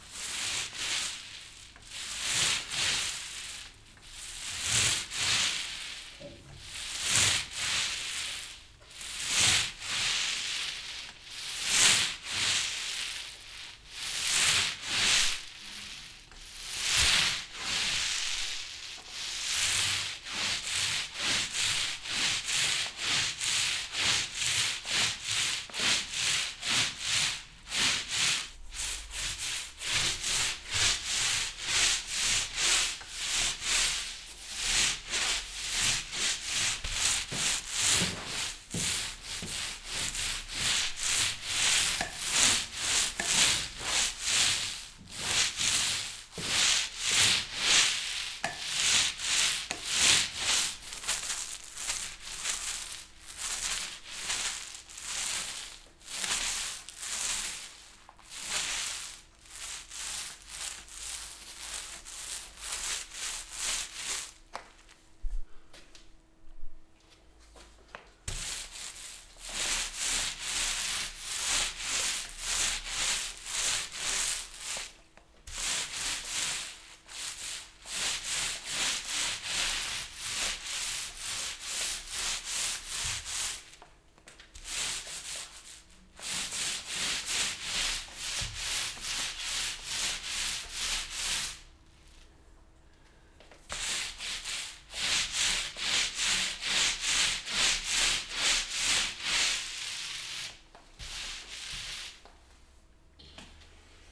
Paint roller
Sonido de un rodillo de pintura, un invento de 1940 de Norman Breakey.
[ENG] Sound of a paint roller, an invention of the 40s of Norman Breakey.
pintura-brocha.wav